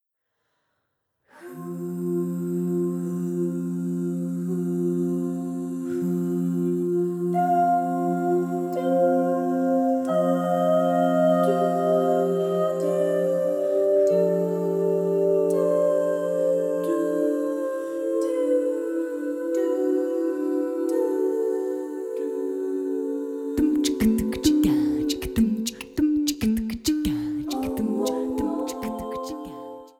diesmal eine reine Jazz-Platte.